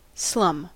Ääntäminen
Synonyymit shantytown Ääntäminen US : IPA : [slʌm] Haettu sana löytyi näillä lähdekielillä: englanti Käännös Substantiivit 1.